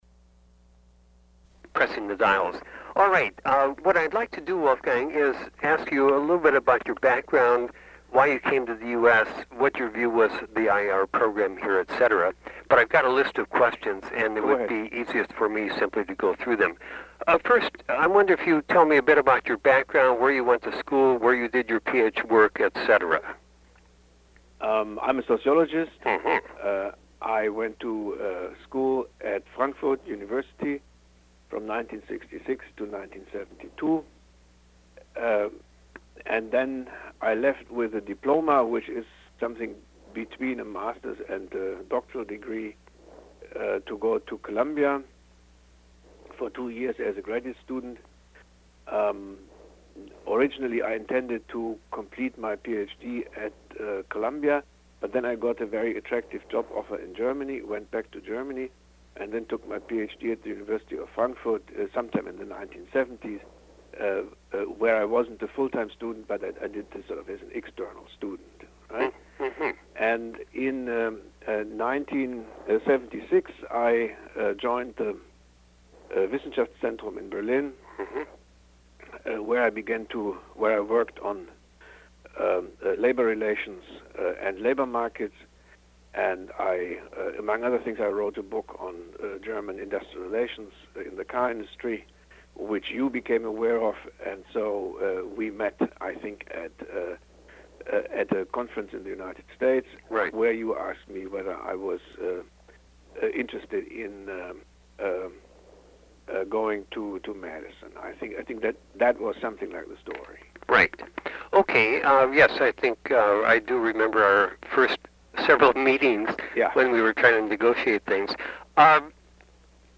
Oral History Interview: Wolfgang Streeck (0708)